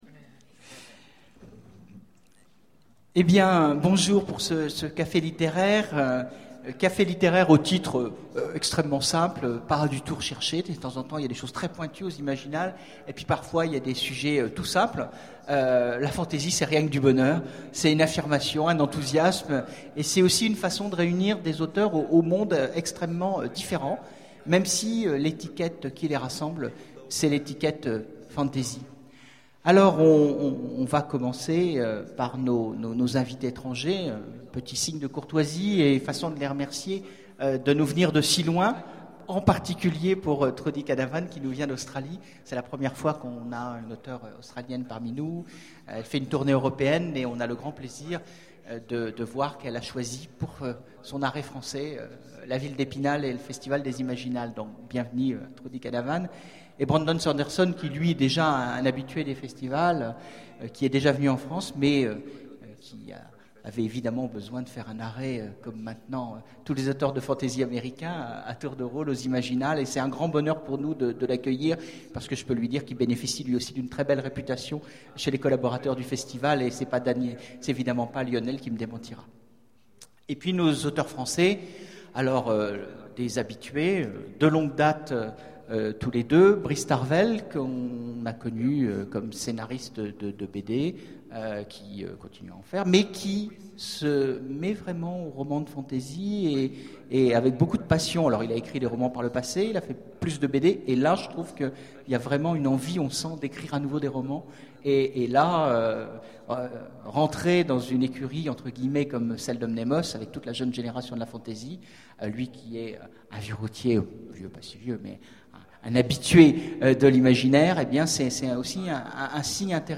Imaginales 2011 : Conférence La Fantasy, c'est rien que du bonheur !